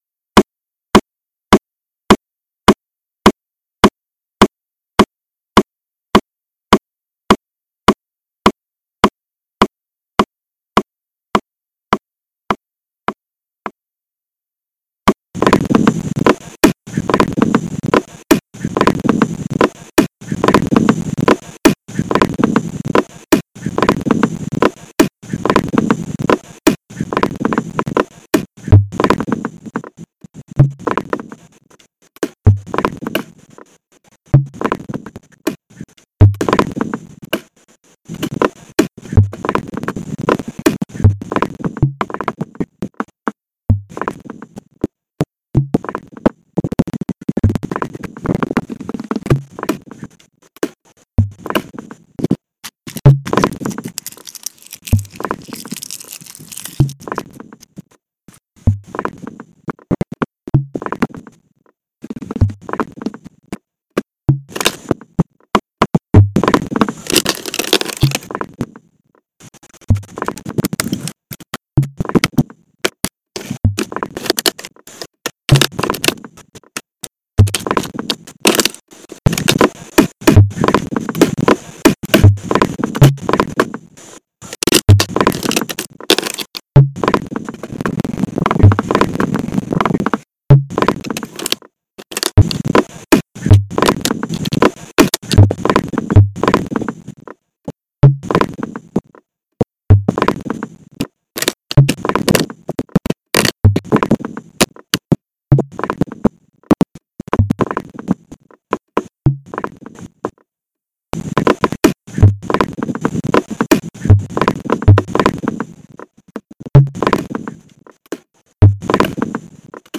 Obra Sonora para 24 Altavoces